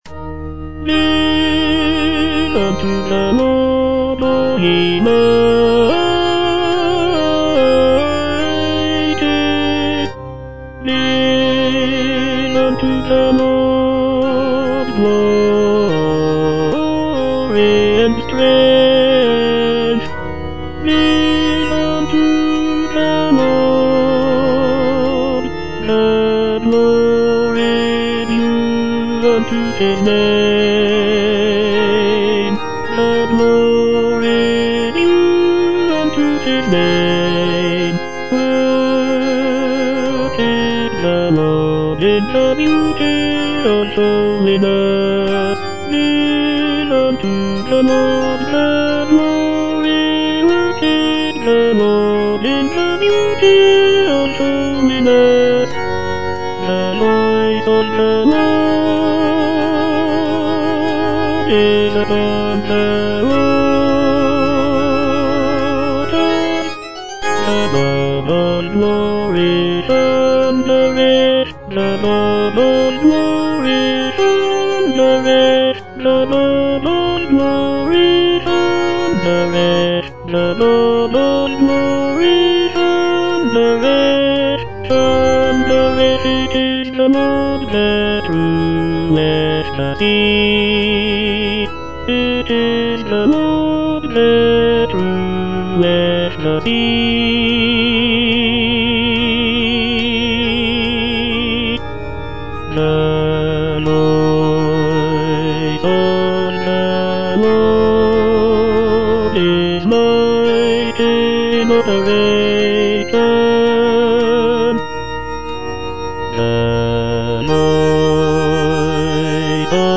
Tenor II (Voice with metronome)
sacred choral work